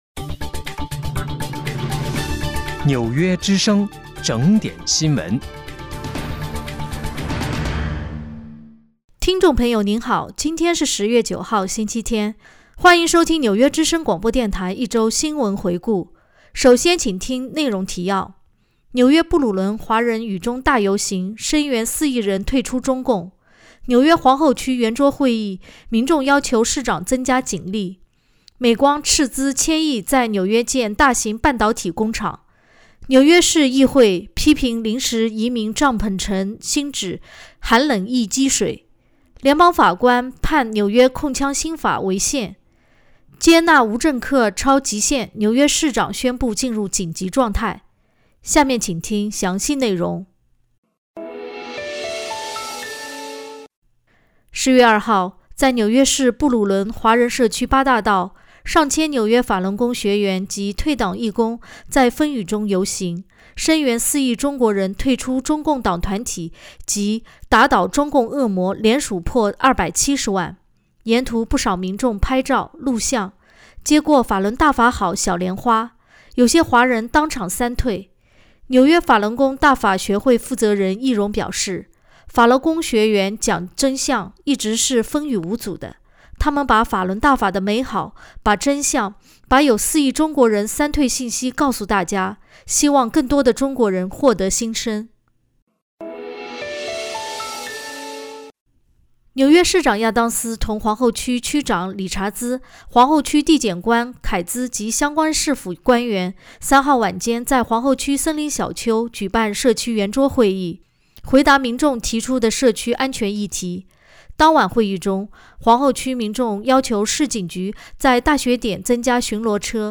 10月9号（星期天）纽约之声广播电台一周新闻回顾。